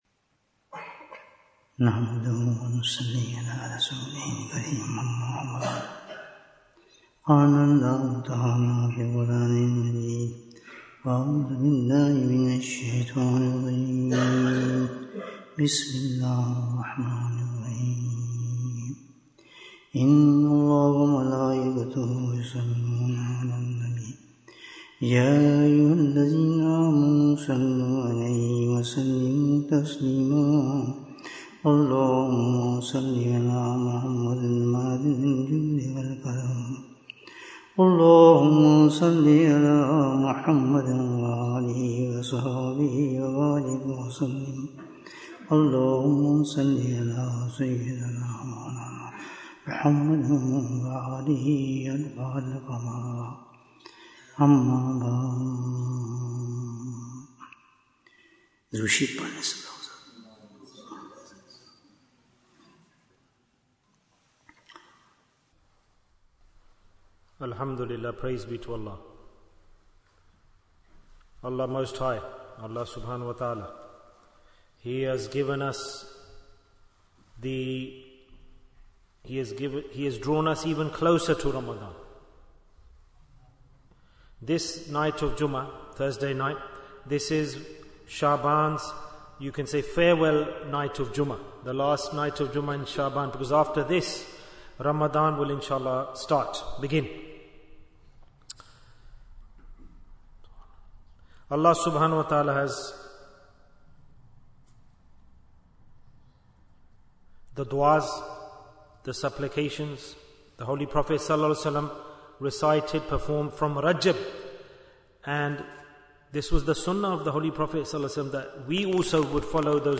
Ramadhan is Here Bayan, 65 minutes27th February, 2025